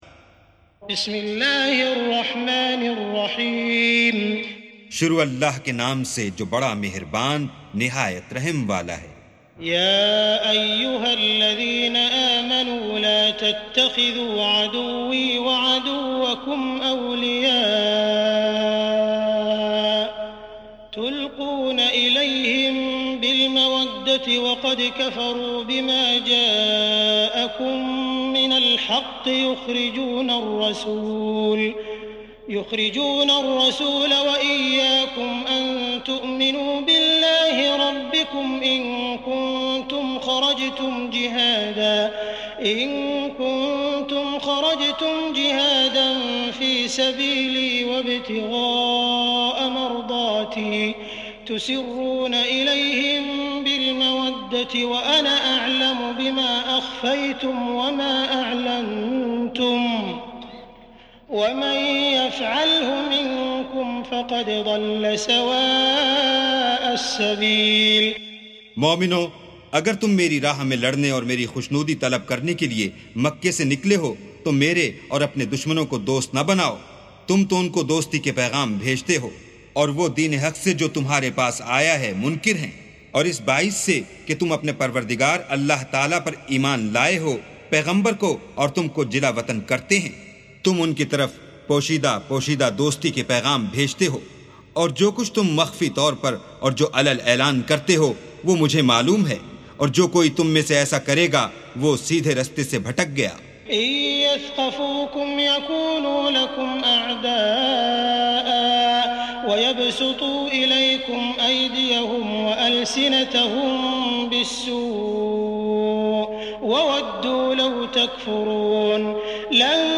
سُورَةُ المُمۡتَحنَةِ بصوت الشيخ السديس والشريم مترجم إلى الاردو